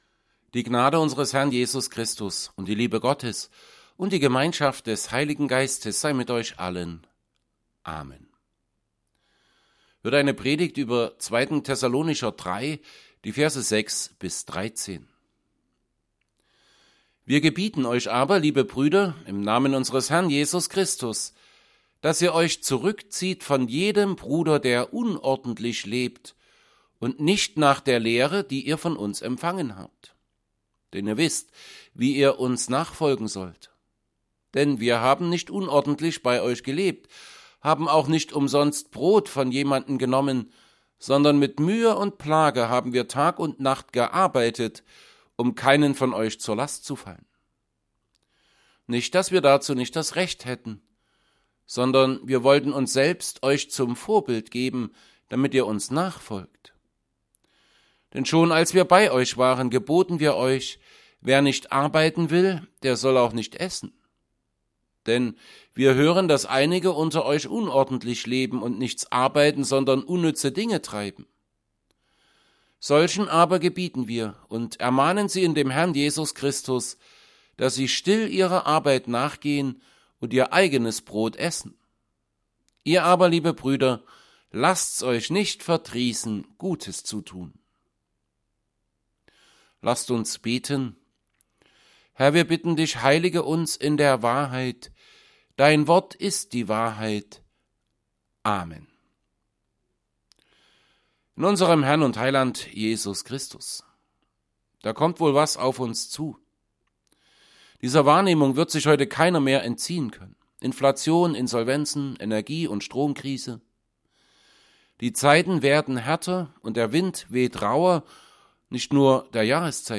Predigt_zu_2_Thessalonicher_3_6b13.mp3